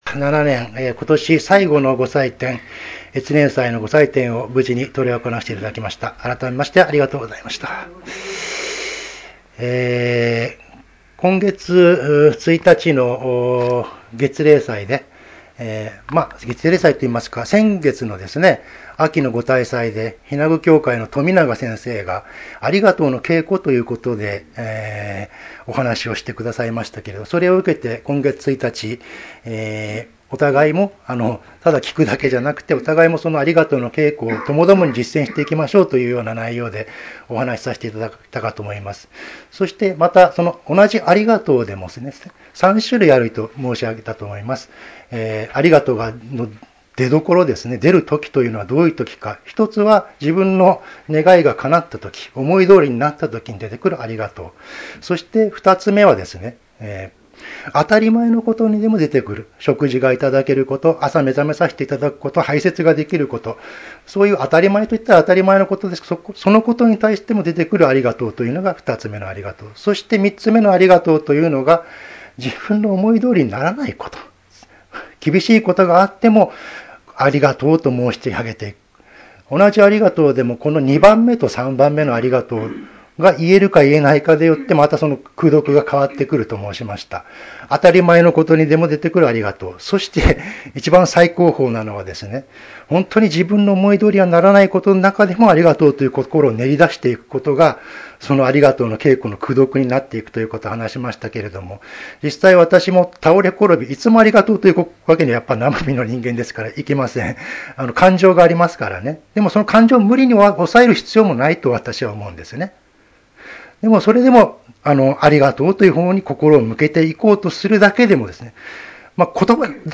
越年祭教話